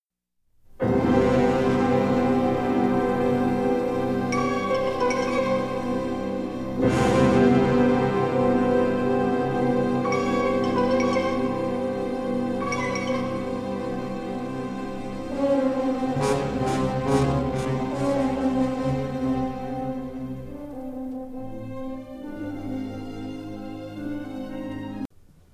vol orkest